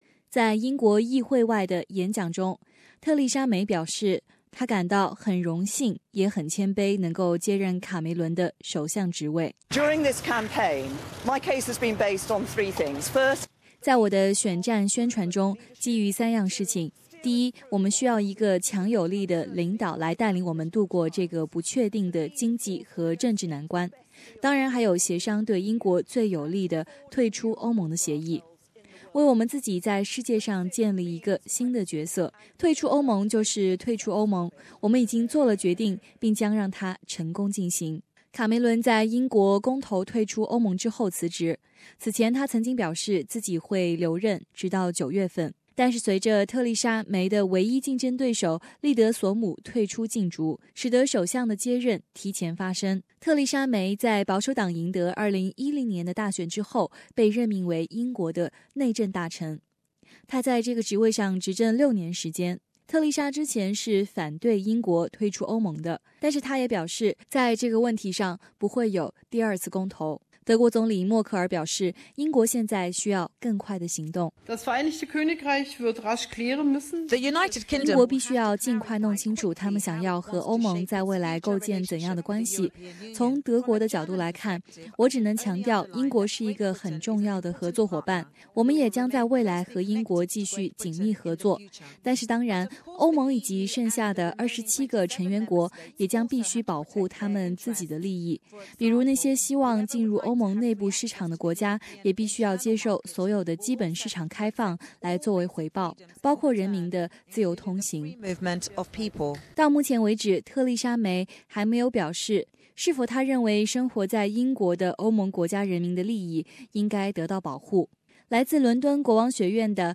SBS 普通話電台